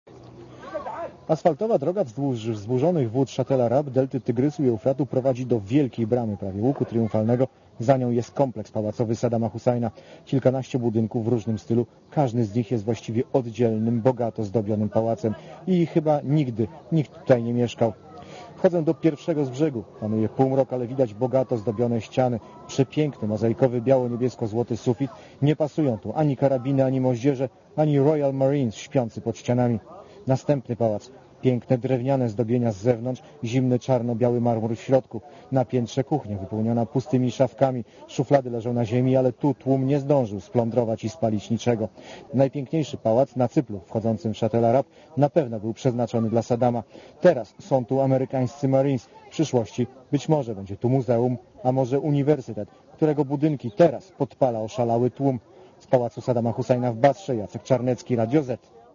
Źródło zdjęć: © RadioZet 08.04.2003 | aktual.: 08.04.2003 22:34 ZAPISZ UDOSTĘPNIJ SKOMENTUJ © (RadioZet) Komentarz audio (230Kb)